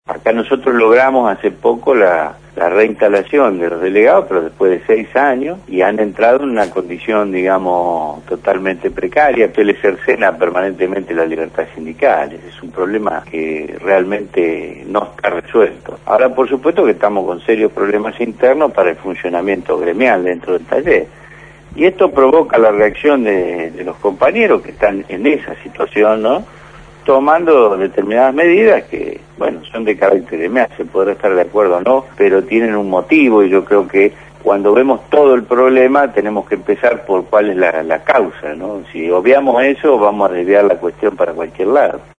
por Radio Gráfica.